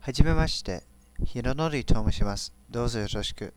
introducing myself with my IRC handle if you're insanely curious as to how I sound.